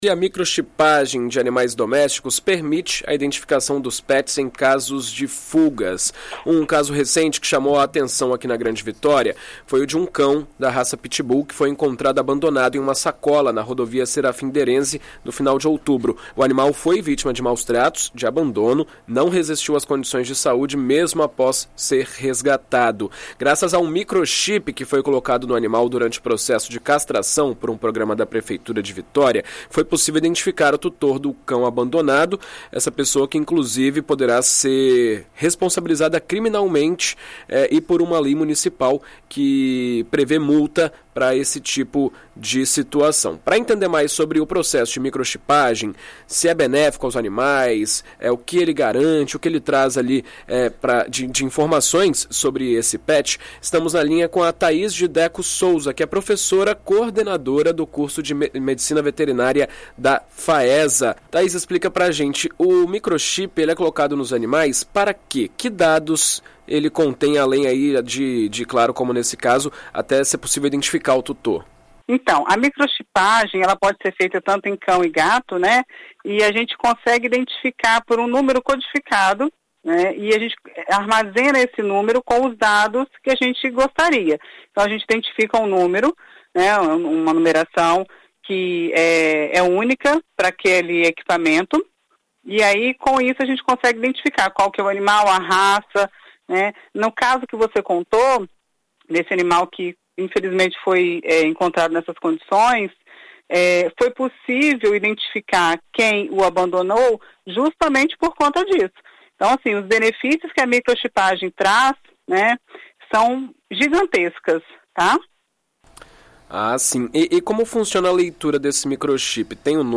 Em entrevista à rádio BandNews FM ES nesta quarta-feira (08)